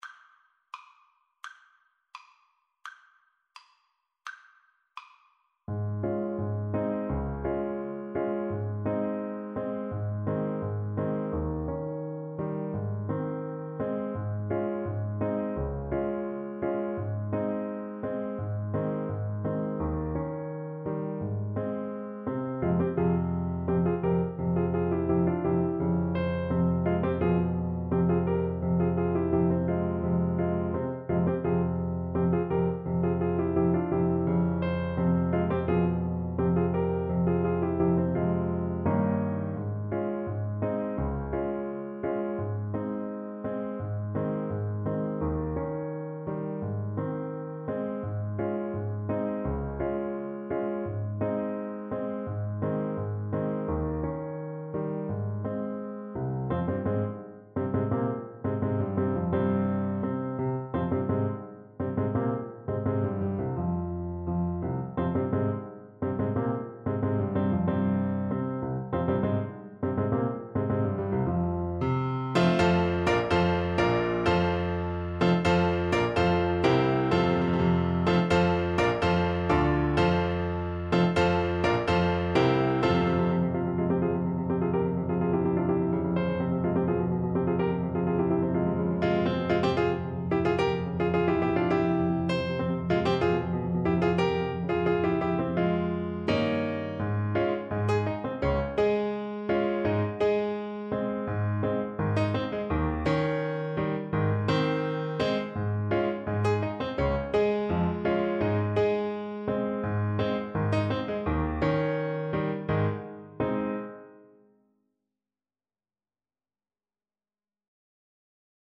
Classical Brahms, Johannes Hungarian Dance No. 3 in F Major Alto Saxophone version
Play (or use space bar on your keyboard) Pause Music Playalong - Piano Accompaniment Playalong Band Accompaniment not yet available transpose reset tempo print settings full screen
2/4 (View more 2/4 Music)
Ab major (Sounding Pitch) F major (Alto Saxophone in Eb) (View more Ab major Music for Saxophone )
Allegretto = 85
Classical (View more Classical Saxophone Music)